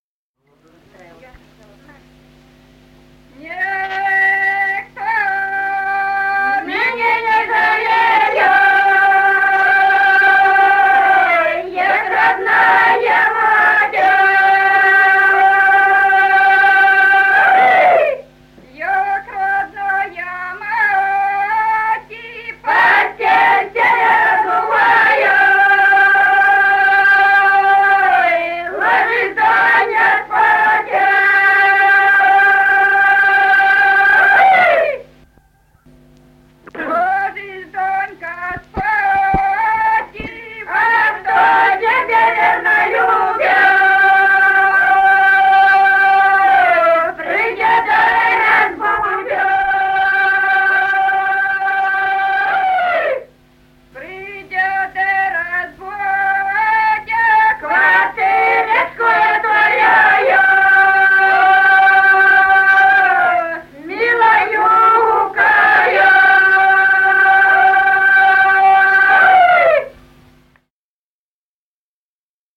Народные песни Стародубского района «Никто меня не жалея», весняная девичья.